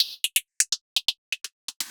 RI_RhythNoise_125-03.wav